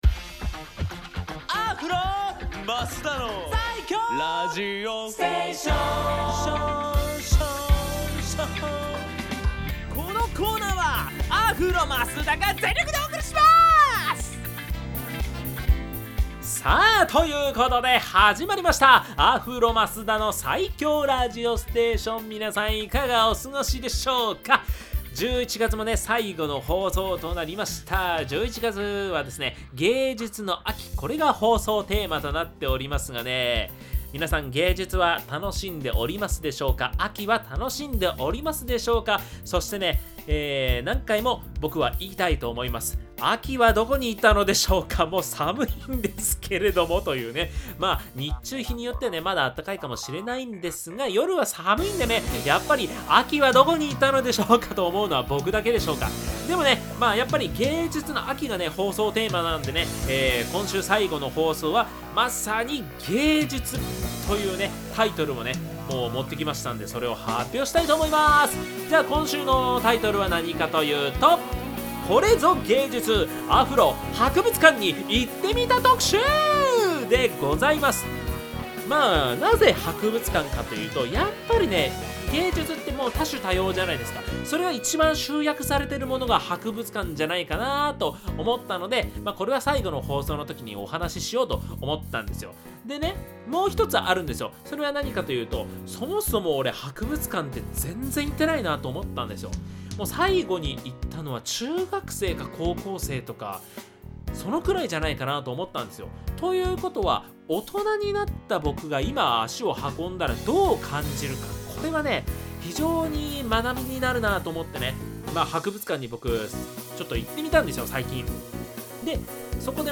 こちらが放送音源です♪